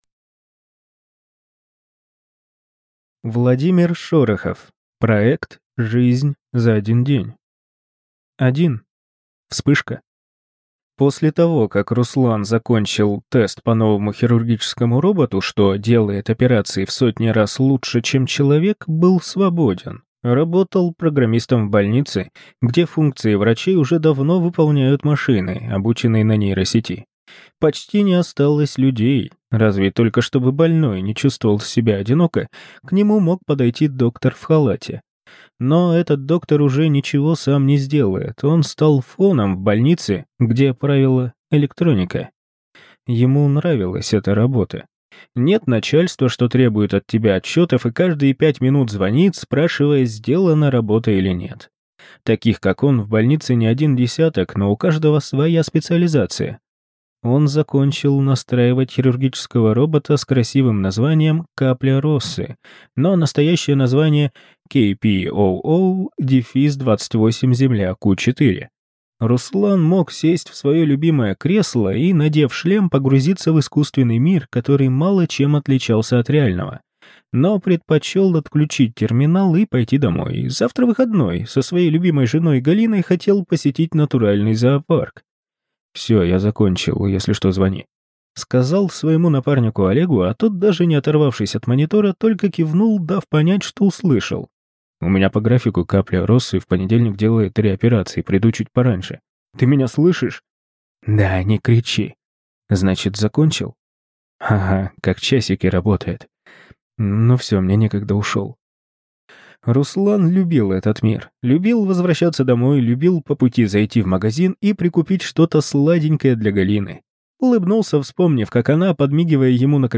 Аудиокнига Проект «Жизнь за один день» | Библиотека аудиокниг